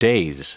Prononciation du mot daze en anglais (fichier audio)
Prononciation du mot : daze